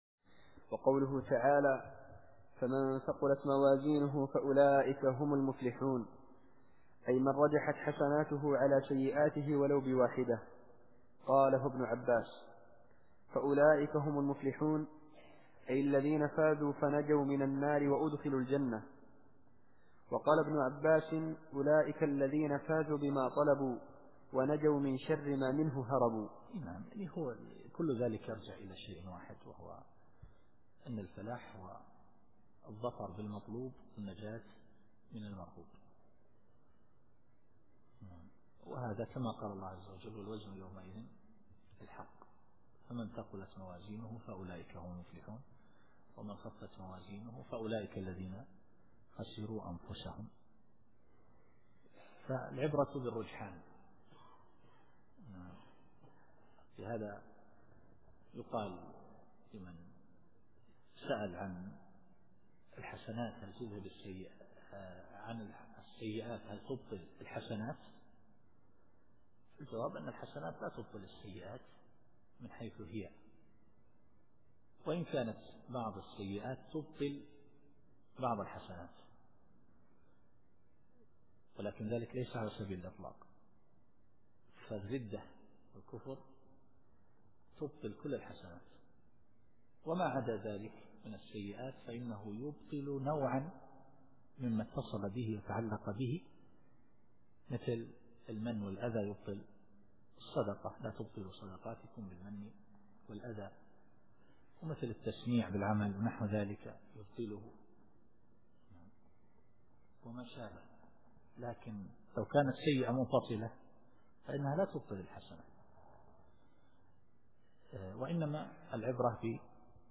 التفسير الصوتي [المؤمنون / 102]